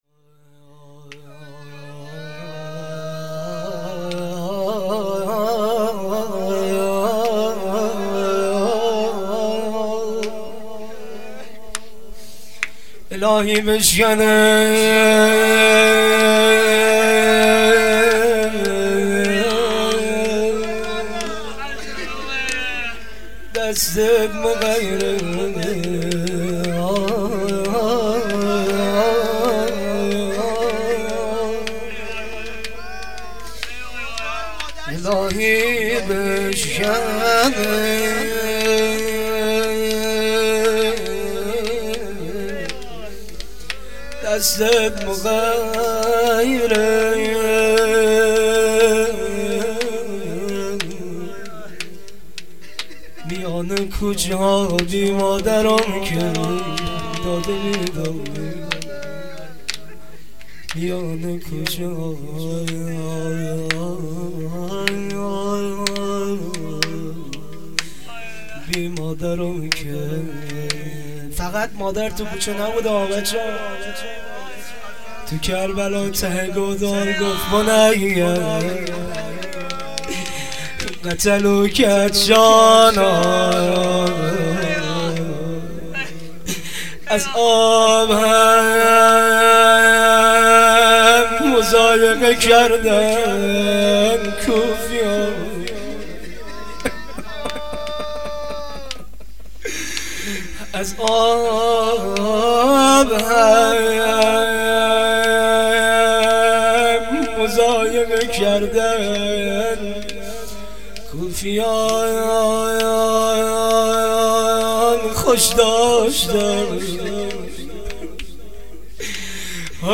هیئت فرهنگی مذهبی فاطمیون درق